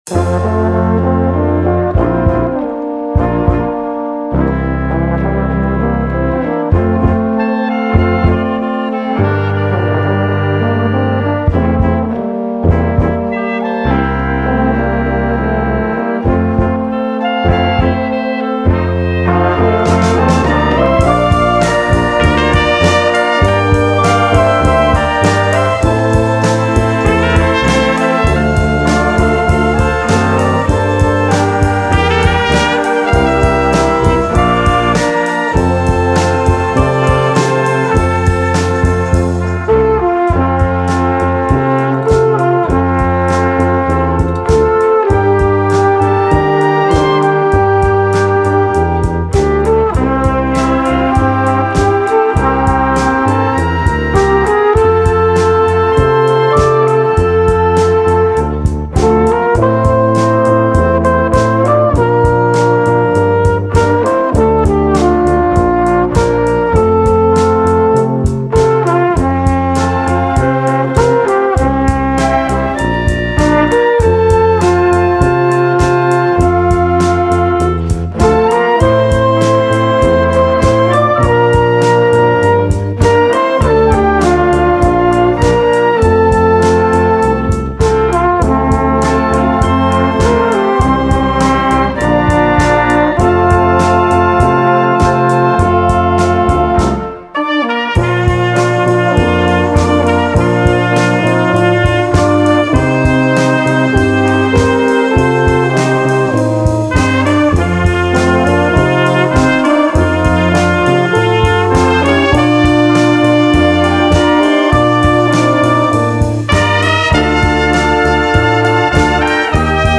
solo Kr